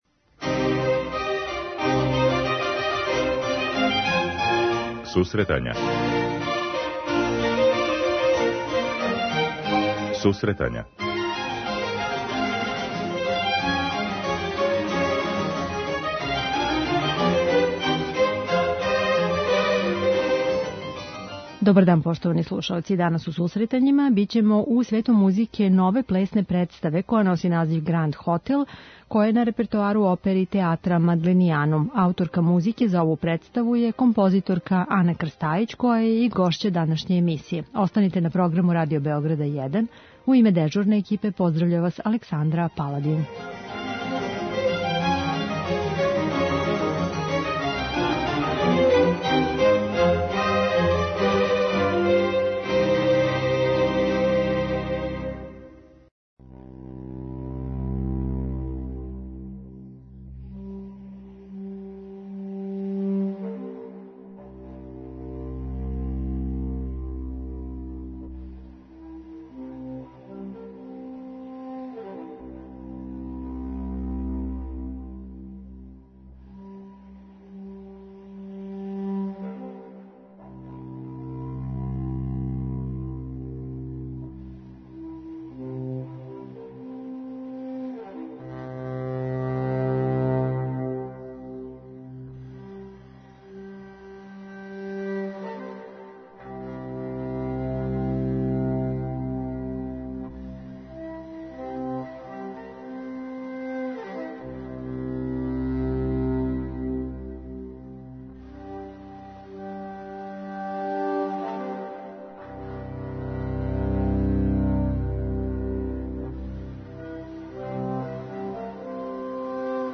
преузми : 10.08 MB Сусретања Autor: Музичка редакција Емисија за оне који воле уметничку музику.